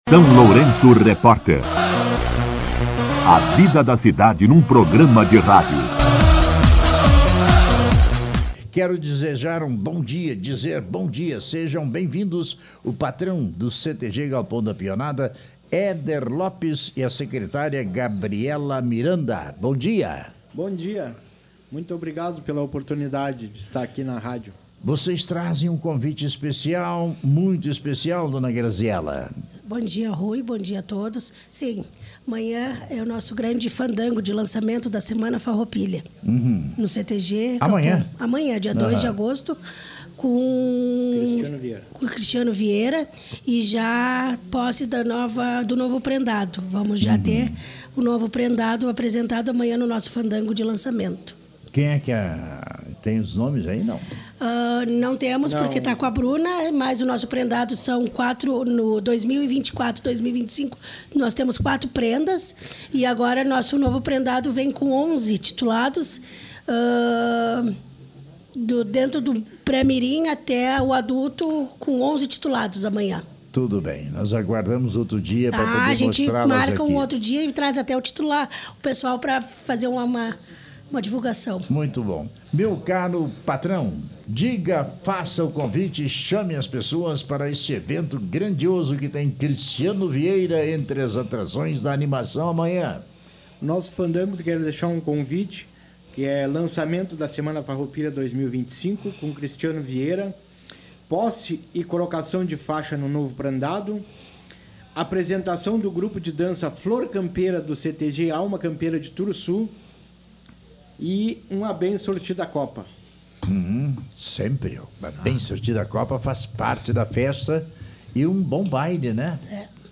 Em visita ao SLR RÁDIO